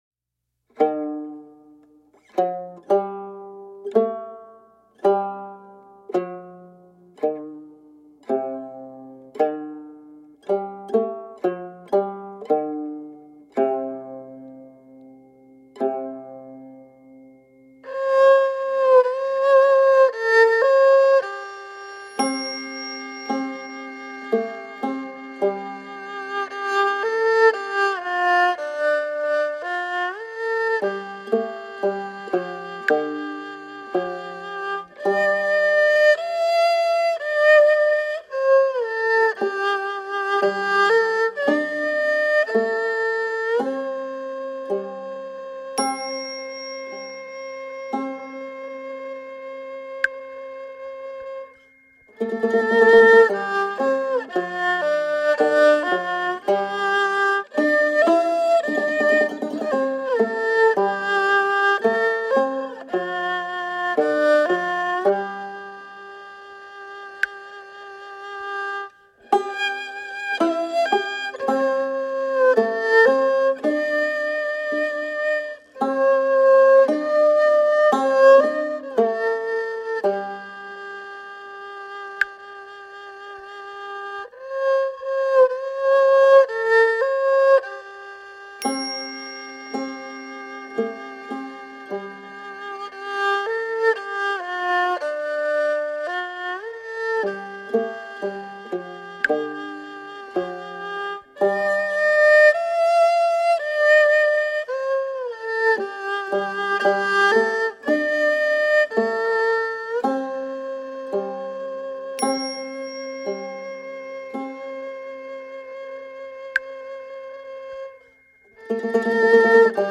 The album was recorded in Shanghai.
Tagged as: World, Instrumental World, Folk